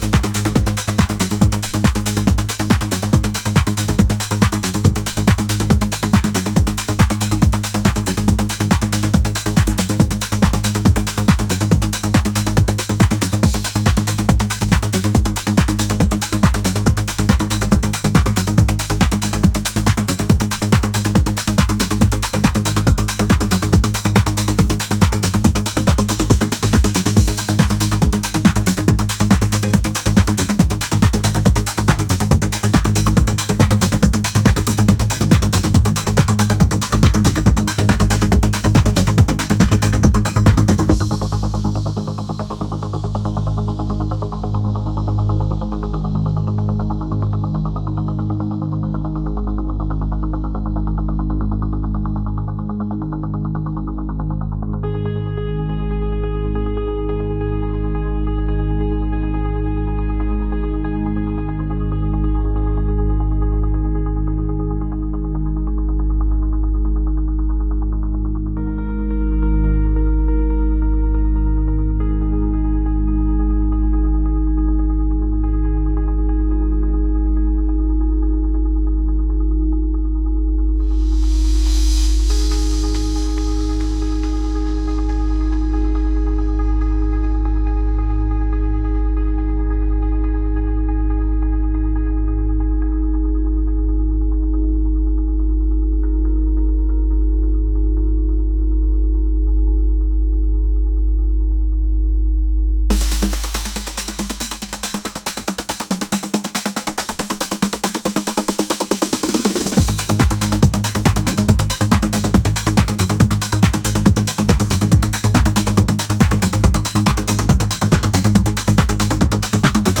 electronic | energetic